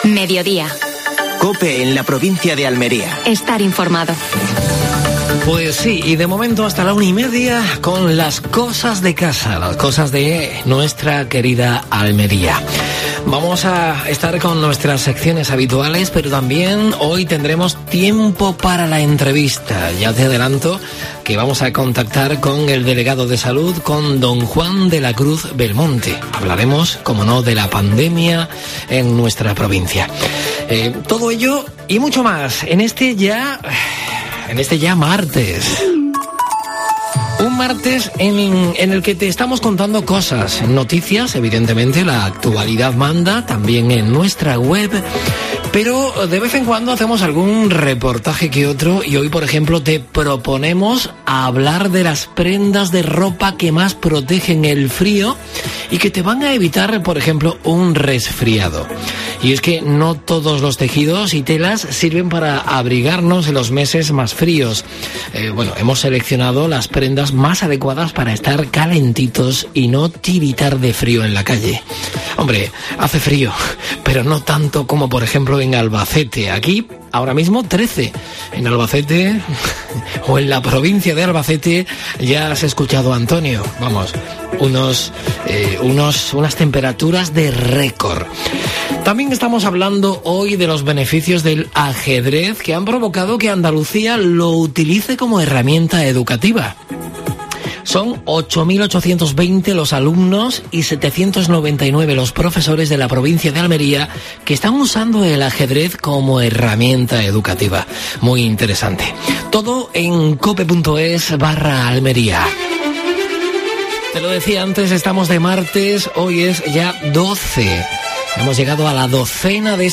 AUDIO: Actualidad en Almería. Entrevista a Juan de la Cruz Belmonte (delegado de Salud de la Junta de Andalucía en Almería).